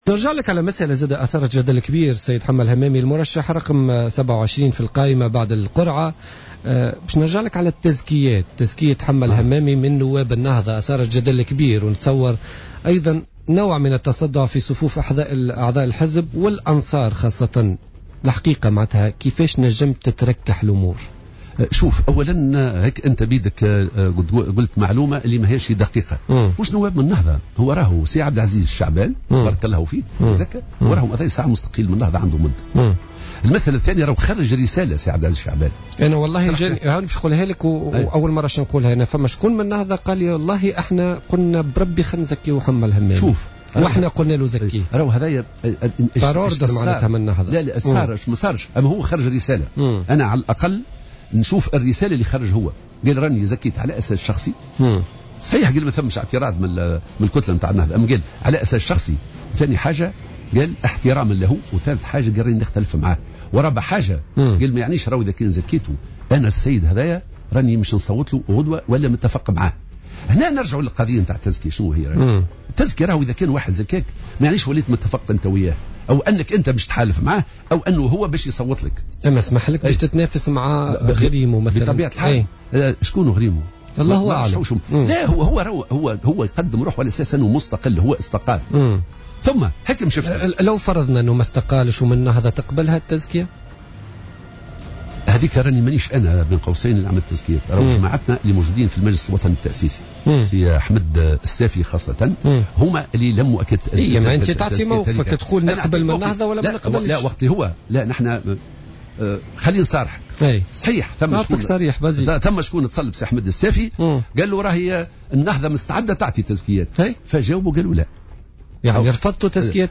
قال المرشح للانتخابات الرئاسية حمة الهمامي ضيف برنامج "بوليتيكا" اليوم الثلاثاء أن الجبهة الشعبية رفضت قبول تزكيات نواب حركة النهضة للانتخابات الرئاسية.